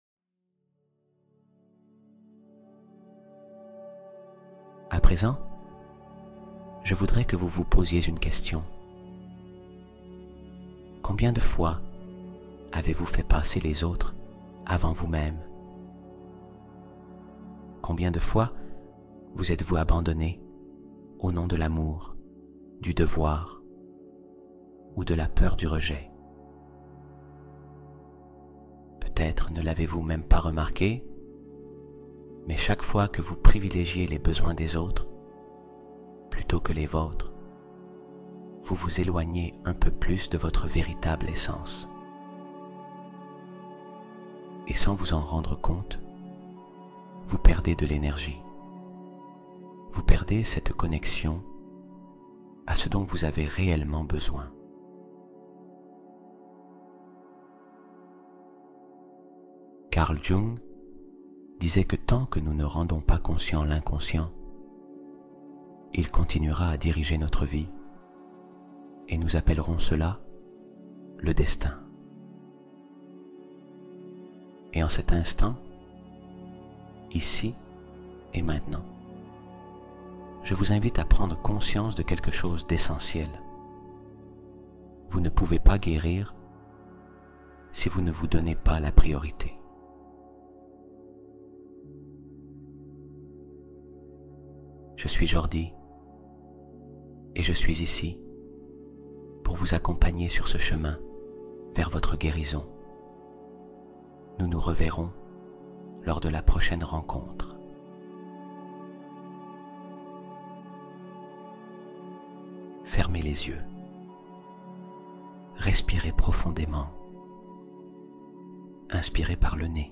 Guérison émotionnelle profonde : processus guidé de libération